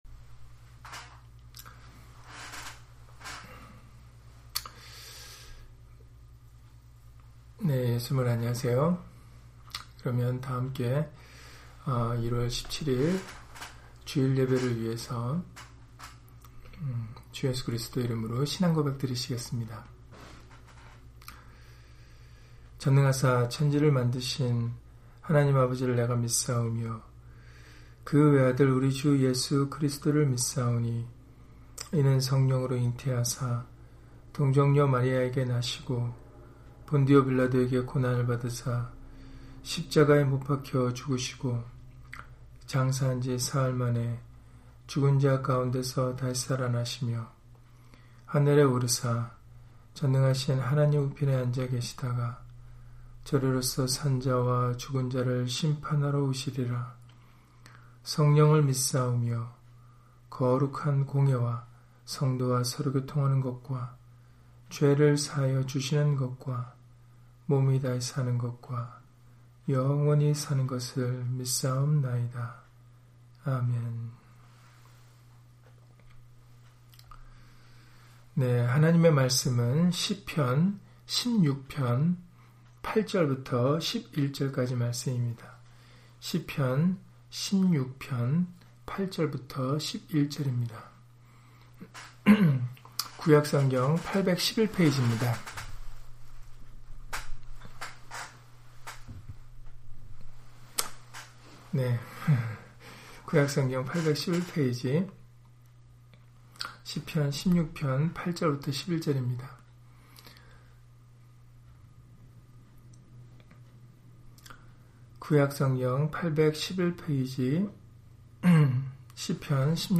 시편 16편 8-11절 [주의 거룩한 자로 썩지 않게 하실 것] - 주일/수요예배 설교 - 주 예수 그리스도 이름 예배당